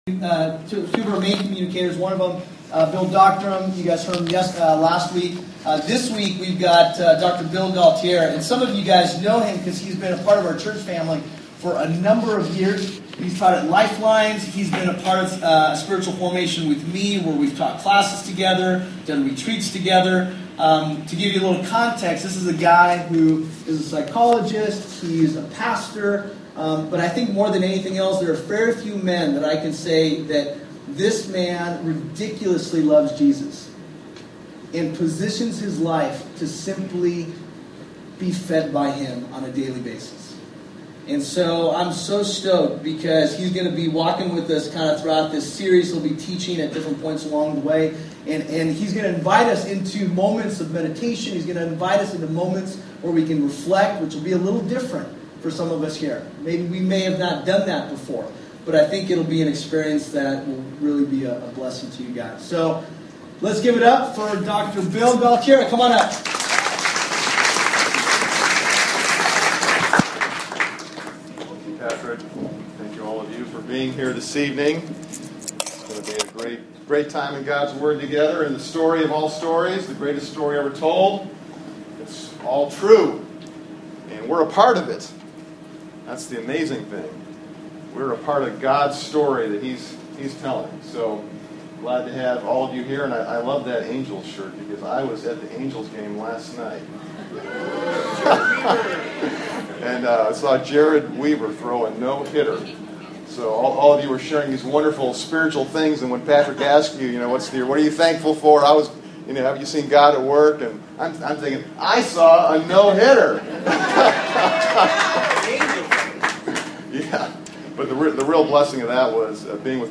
This message was given at The Crossing Church in Costa Mesa, CA on 5-3-2012.